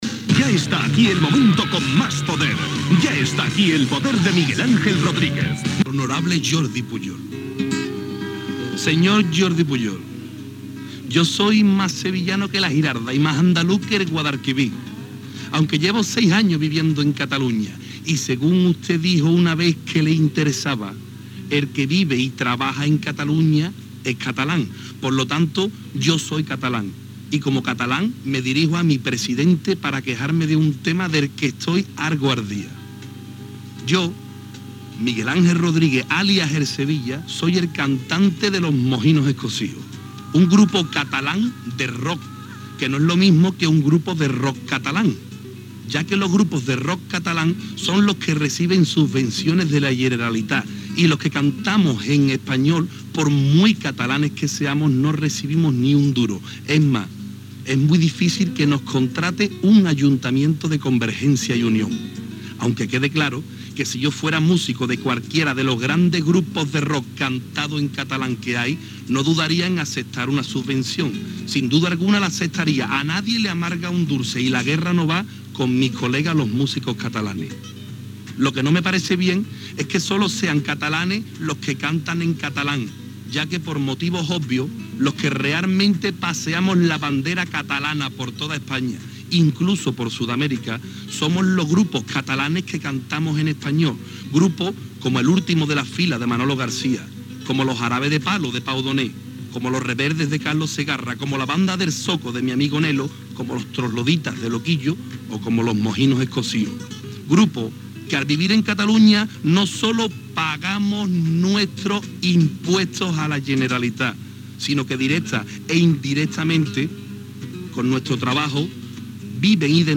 Crítica de Miguel Ángel Rodríguez "El Sevilla" dirigida al president de la Generalitat, Jordi Pujol sobre com es donaven les subvencions als grups de música catalans.
Entreteniment